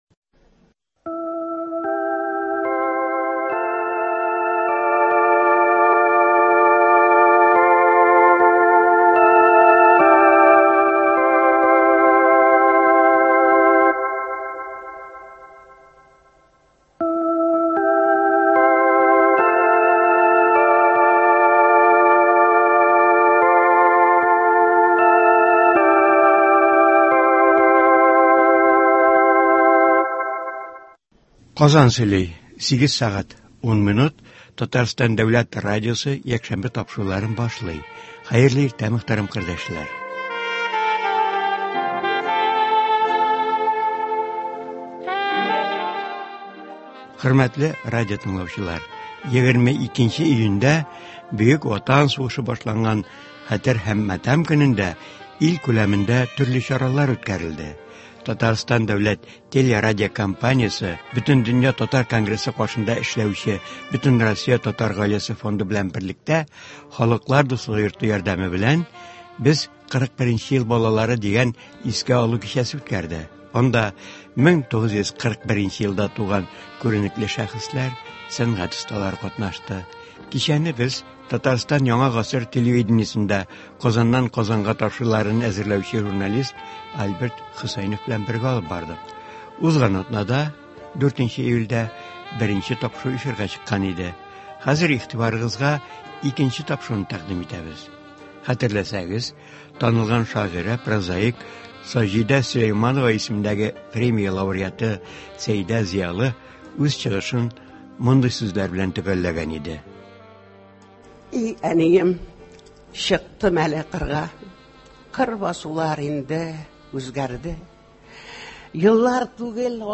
Татарстан дәүләт телерадиокомпаниясе Бөтендөнья татар конгрессы каршында эшләүче Бөтенроссия Татар гаиләсе фонды белән берлектә “Халыклар дуслыгы” йорты ярдәме белән “Без 41 ел балалары” дигән искә алу кичәсе үткәрде. Анда 1941 елда туган күренекле шәхесләр, сәнгать осталары катнашты. Бу кичәнең язмасы (2 нче тапшыру) 11 июльдә 8 сәгать 10 минутта эфирга чыгачак.